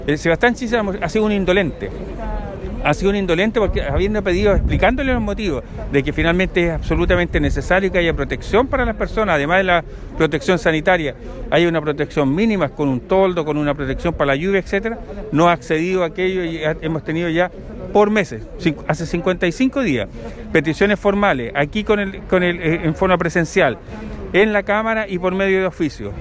Situación que empeoró con el pago del 10% de fondos de AFP, por lo que el diputado Celis reiteró el llamado a tomar medidas al respecto.